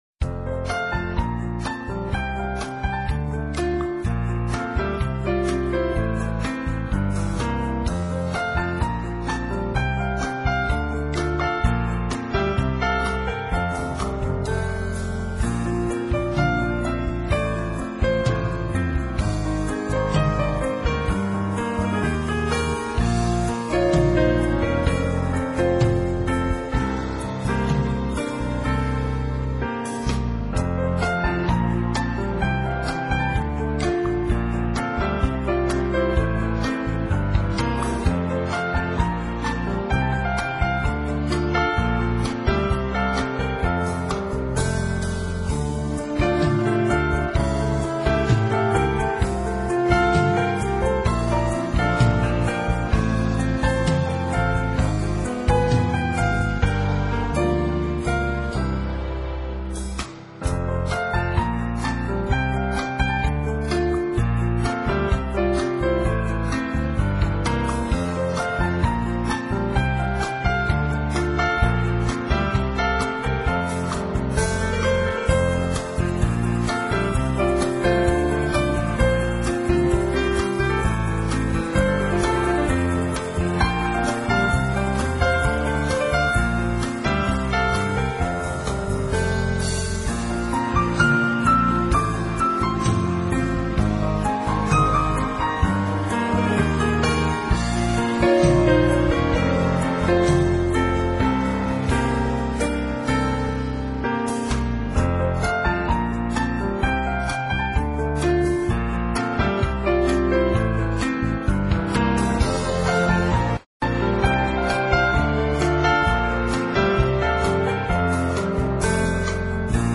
在27首单曲中大部分是钢琴独奏。